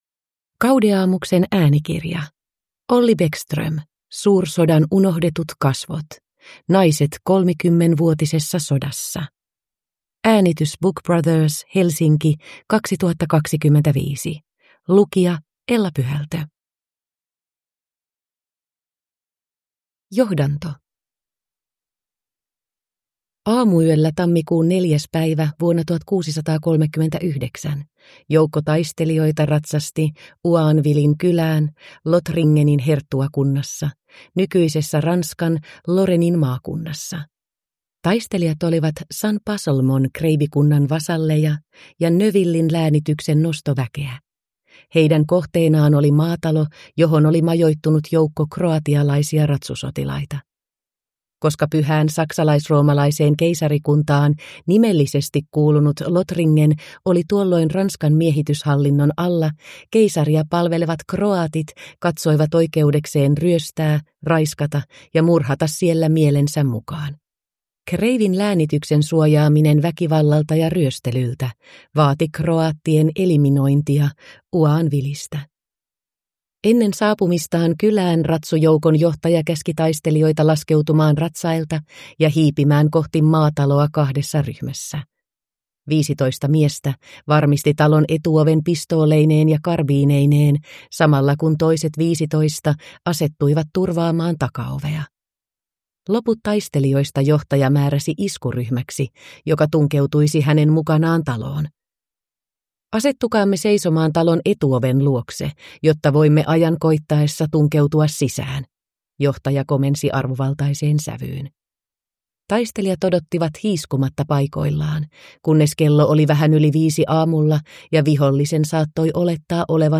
Suursodan unohdetut kasvot – Ljudbok